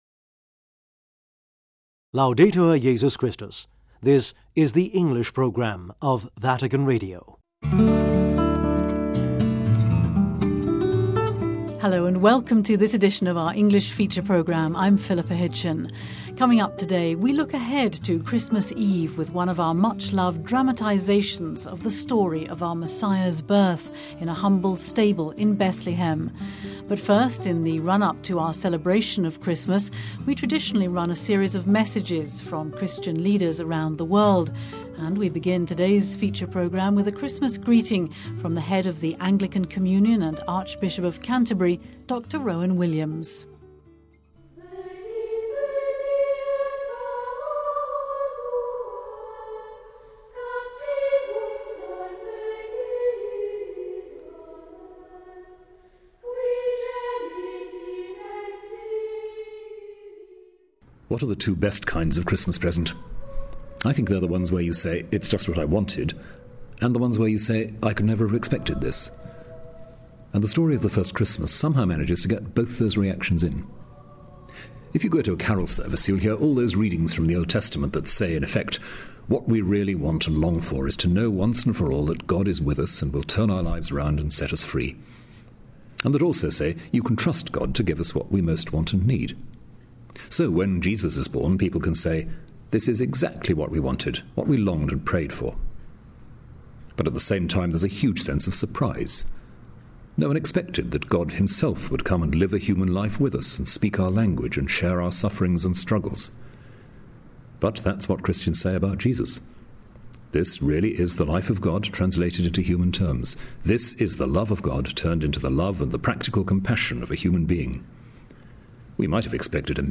C hristmas messages : In our series of seasonal reflections and greetings from Christian leaders around the world, we hear today from the head of the worldwide Anglican communion and Archbishop of Canterbury,Dr Rowan Williams... ' That Night in Bethlehem' : We look ahead to the celebration of Christmas Eve with one of our much loved dramtisations of the story of our Messiah's birth in a humble stable in Bethlehem....